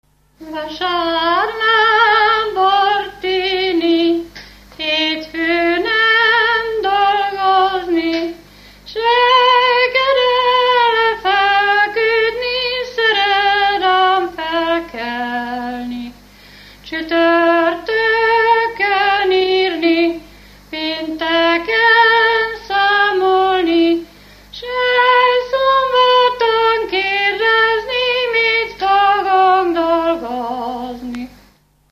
Felföld - Gömör és Kishont vm. - Csucsom
ének
Stílus: 5. Rákóczi dallamkör és fríg környezete
Kadencia: 4 (4) 4 1